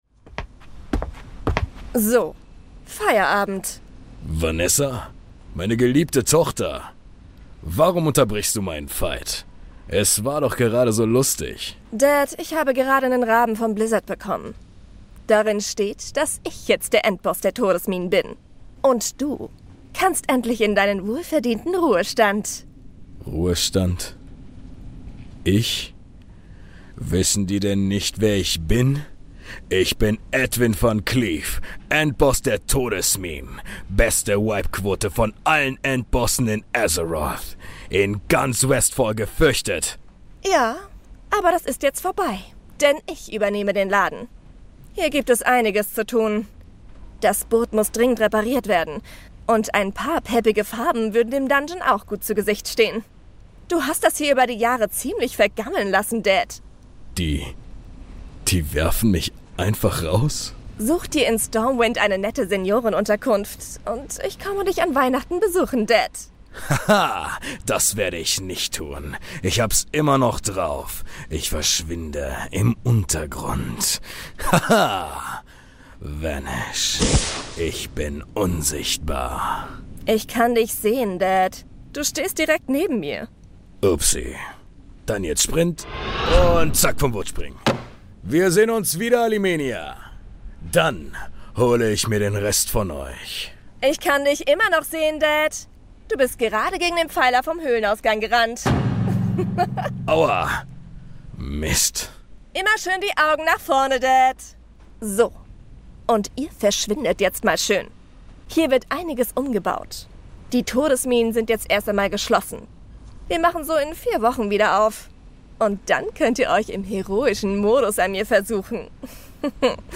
Hörspiel - Allimania